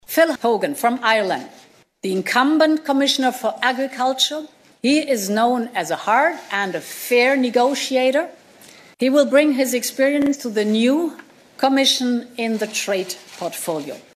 Details of his appointment were made in Brussels this morning by EU President-designate Ursula Von Der Leyen who described Phil Hogan as a tough negotiator.